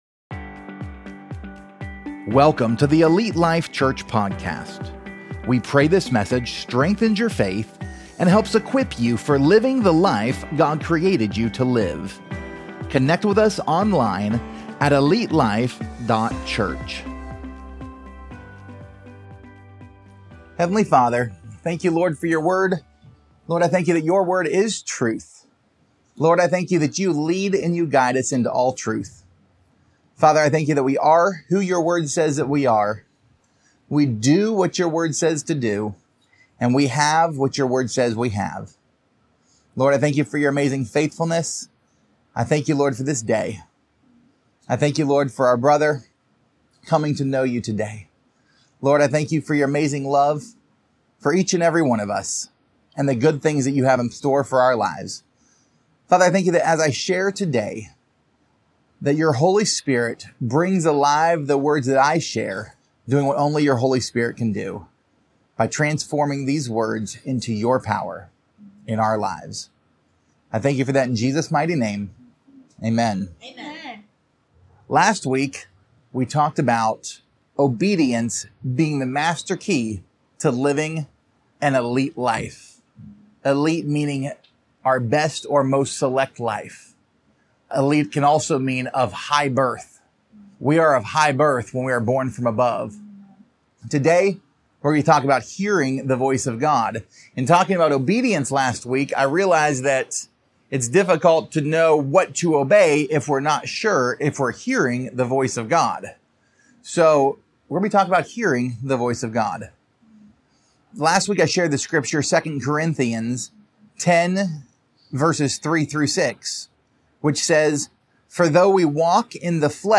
Pt 1: Dreams, Whispers, and the Word | Sermon